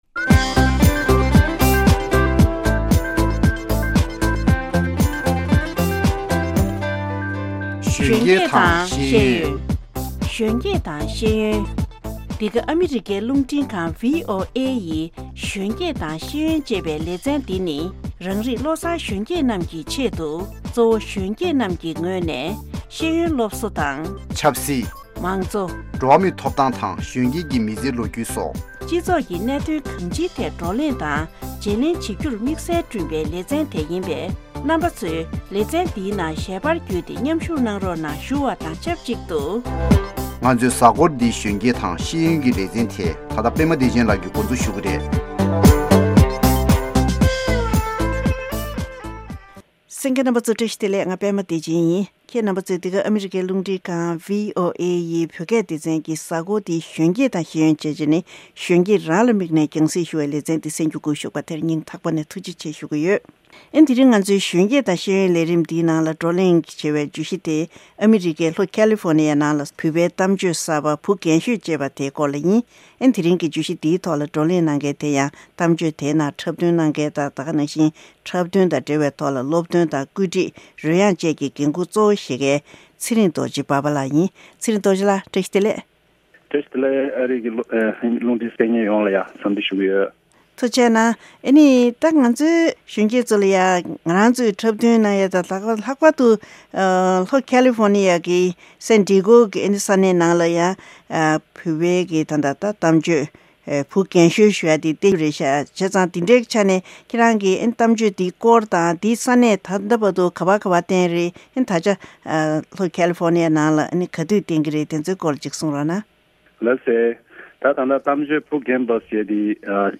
Interview with Actor